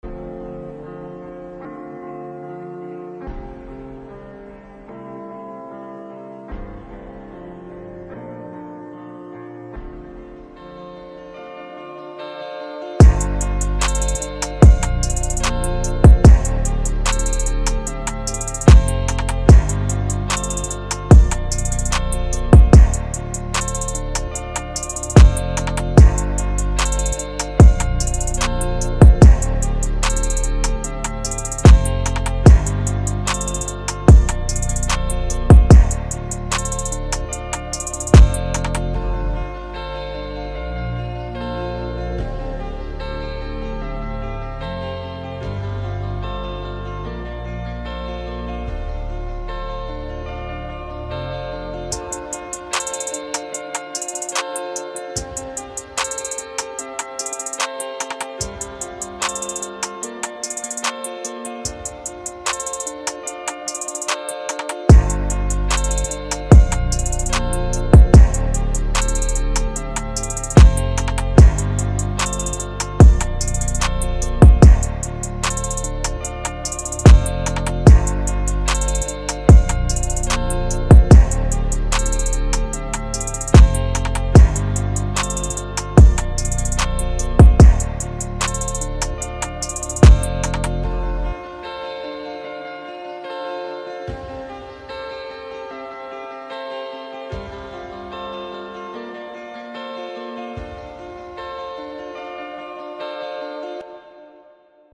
6 Claps
5 FX
4 Open & 4 Closed Hats
7 Kicks
10 Percs
7 Snares
8 Vox
8 LOOPS